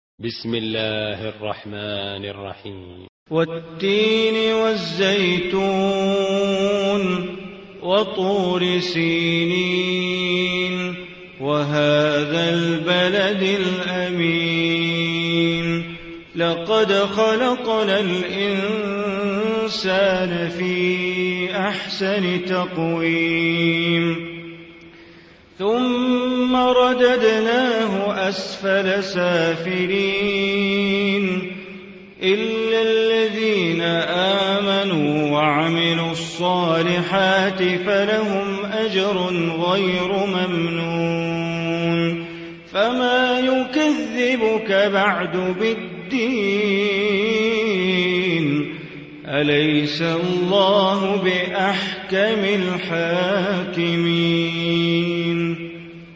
Surah Tin Recitation by Sheikh Bandar Baleela
95-surah-tin.mp3